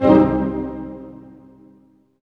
Index of /90_sSampleCDs/Roland LCDP08 Symphony Orchestra/HIT_Dynamic Orch/HIT_Tutti Hits